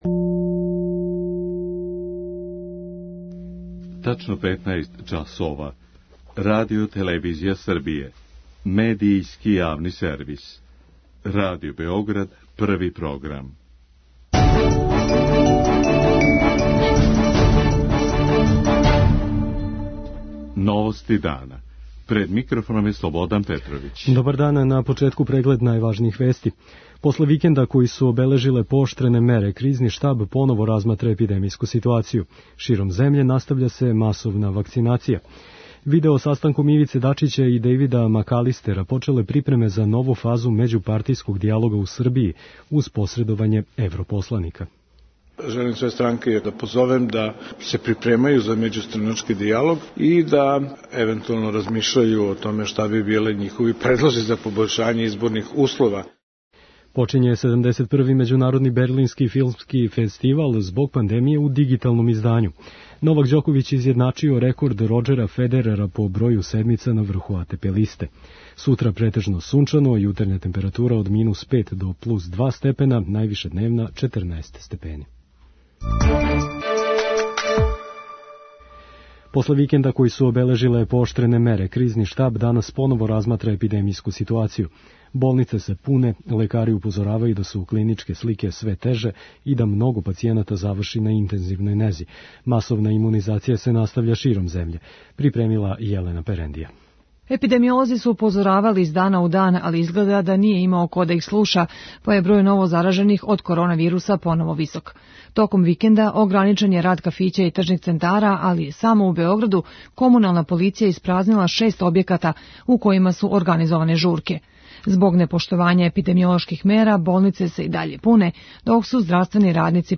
Болнице се пуне, лекари упозоравају да су клиничке слике све теже и да много пацијената заврши на интензивној нези. преузми : 7.67 MB Новости дана Autor: Радио Београд 1 “Новости дана”, централна информативна емисија Првог програма Радио Београда емитује се од јесени 1958. године.